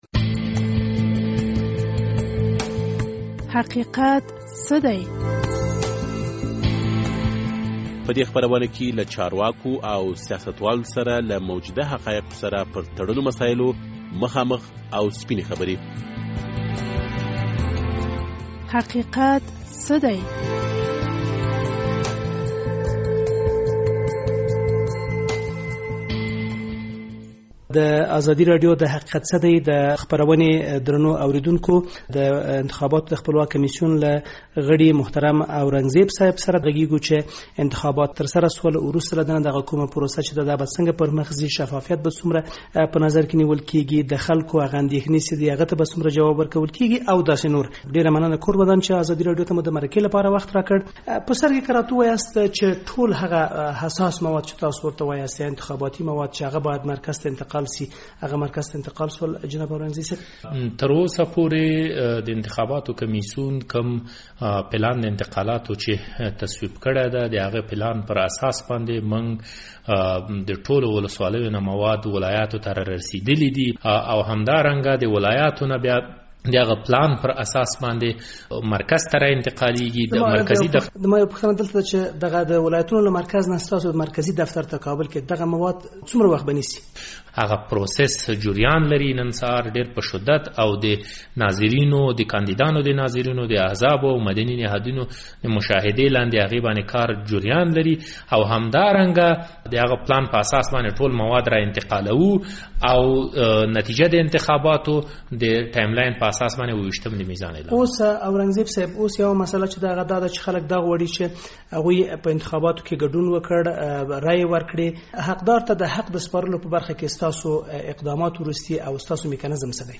د "حقیقت څه دی؟" د دې اوونۍ په خپرنه کې مو د انتخاباتو د خپلواک کمېسیون له غړي ښاغلي اورنګزیب سره مرکه کړې.